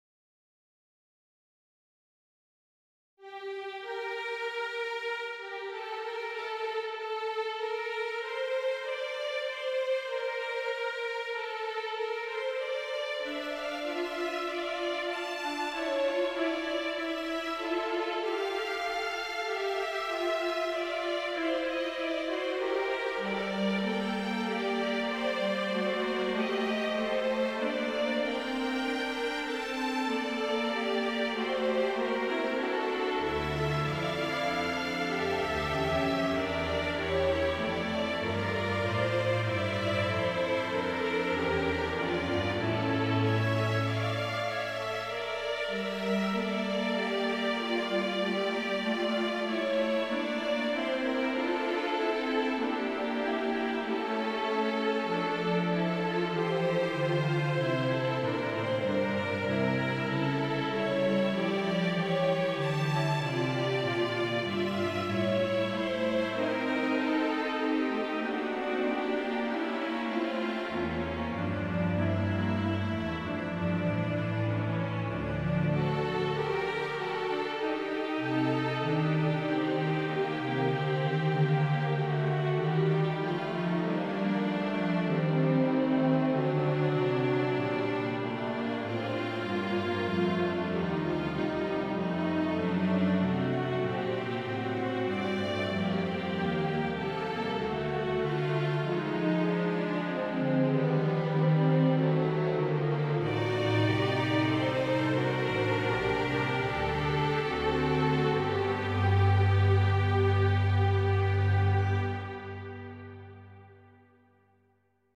mp3 string ensemble